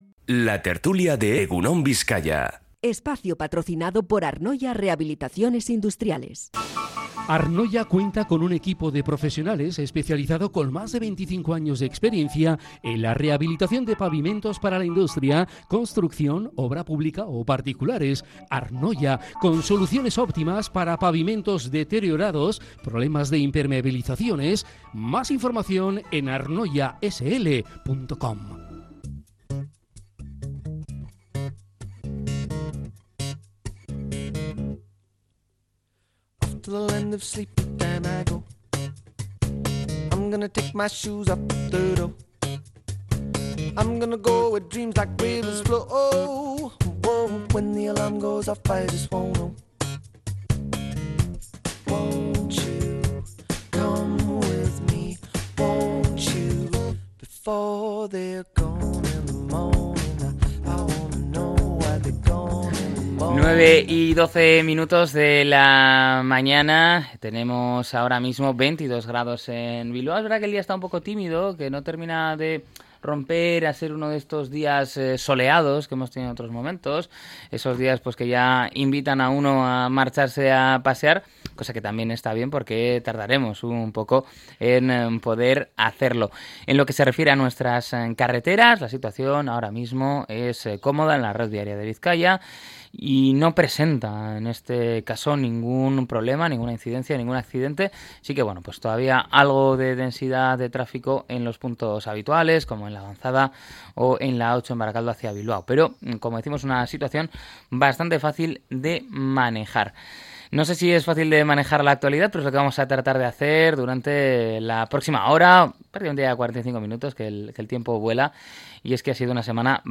La tertulia 11-07-25.